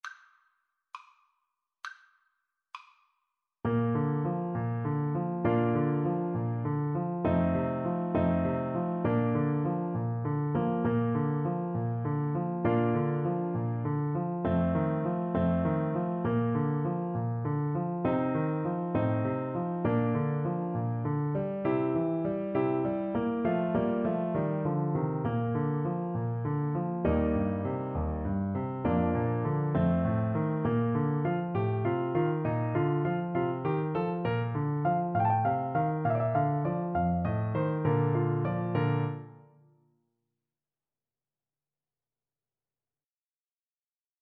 Play (or use space bar on your keyboard) Pause Music Playalong - Piano Accompaniment Playalong Band Accompaniment not yet available transpose reset tempo print settings full screen
~ = 100 Fršhlich
Bb major (Sounding Pitch) C major (Trumpet in Bb) (View more Bb major Music for Trumpet )
6/8 (View more 6/8 Music)